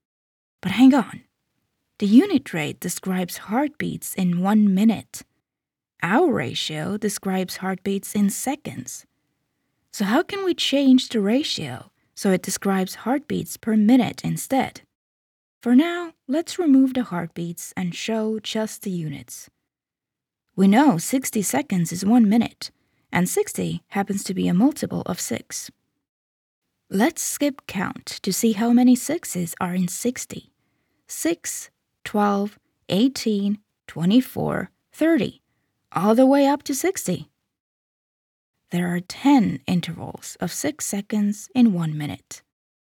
E-learning
Soy locutora estonia nativa y trabajo tanto en estonio como en inglés, ¡con un ligero acento!
Mi acento es lo suficientemente suave como para ser fácilmente comprensible, además de ser adecuado para conectar con gente de todo el mundo.